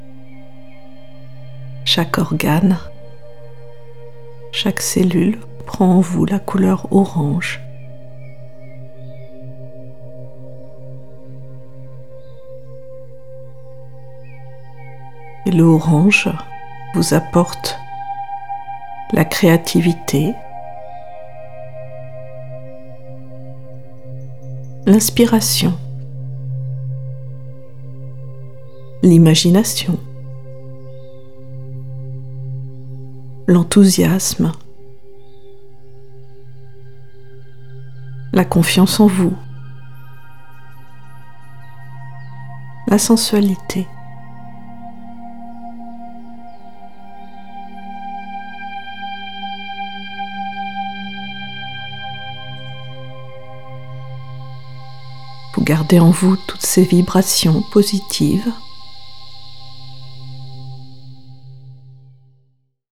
Séance prénatale ou postnatale de relaxation hypnotique adaptée aussi bien au père qu’à la mère.